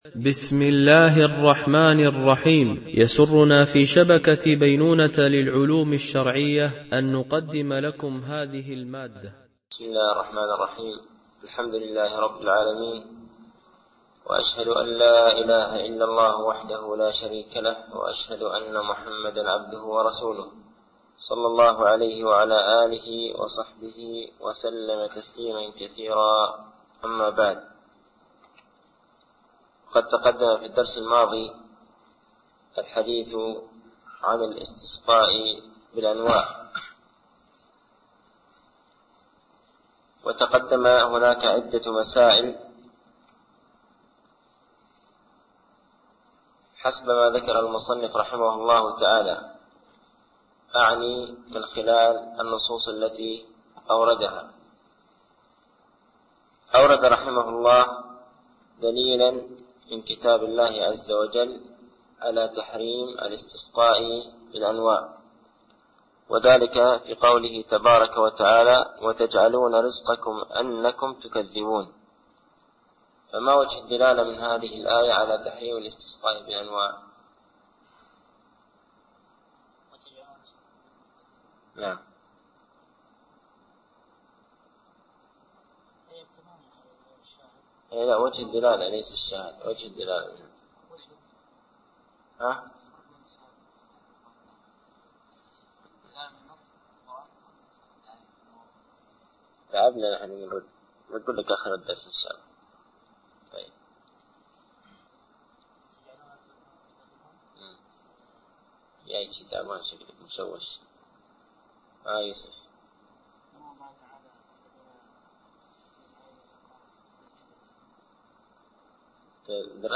) الألبوم: شبكة بينونة للعلوم الشرعية التتبع: 108 المدة: 72:18 دقائق (16.59 م.بايت) التنسيق: MP3 Mono 22kHz 32Kbps (CBR)